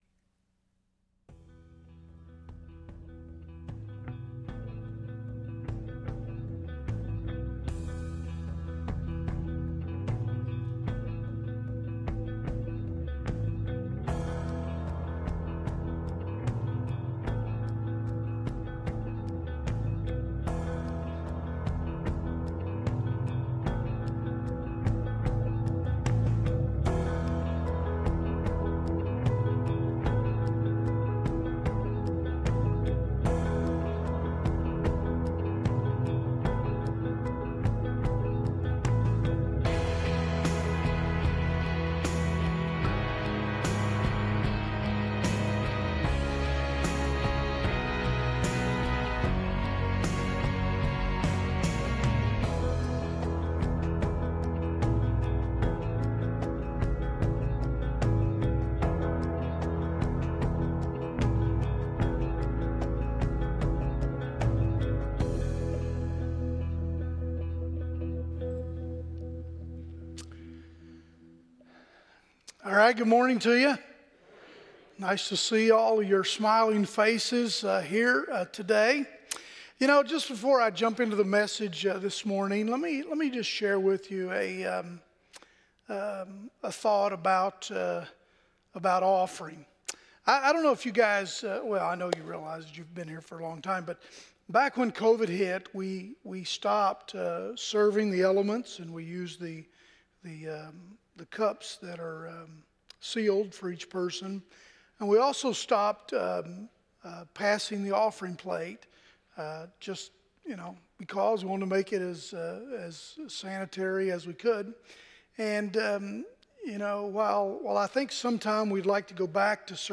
Sermon Description